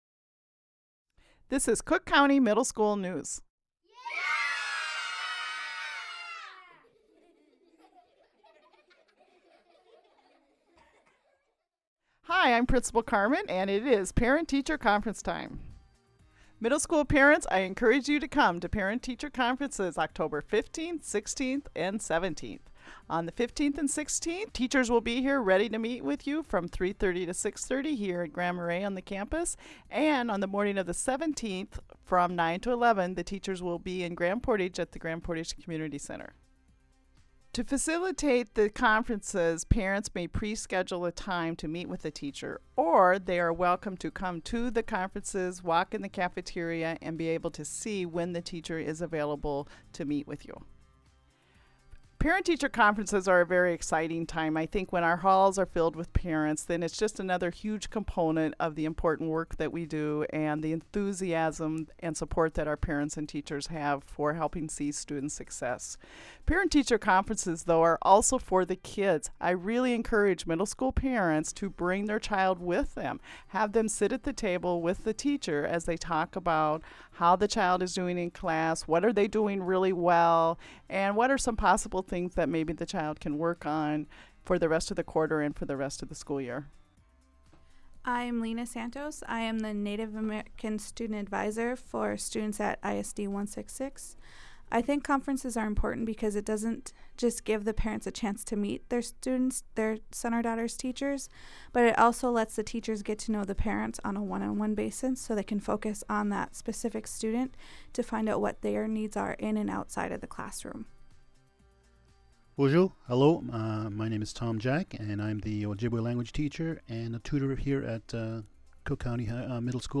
School News